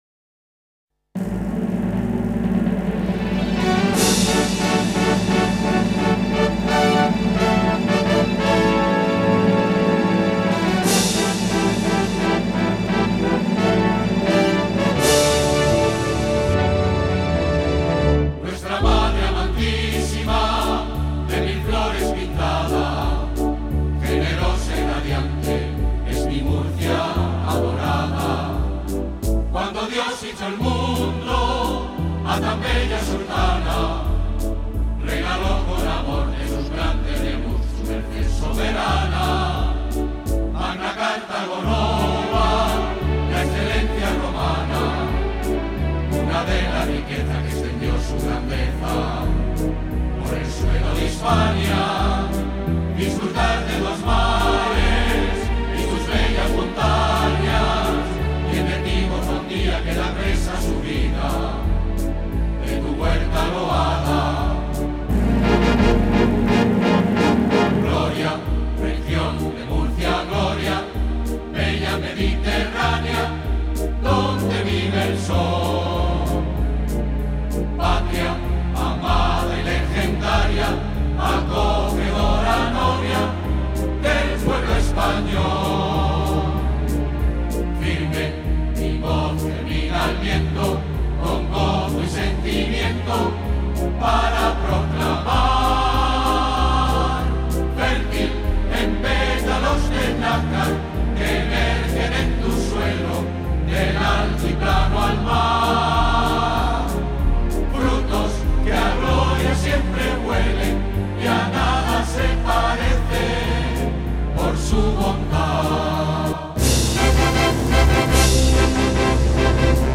está interpretada por un coro